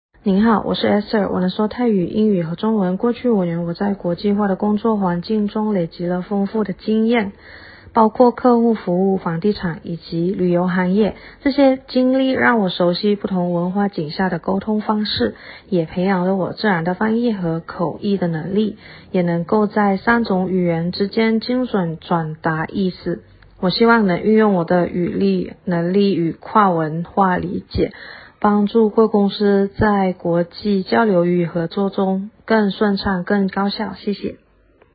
我们口译师专业自介音档试听如下，都是派出语言流利的跨国语言译者服务您：
✔中文自介音档: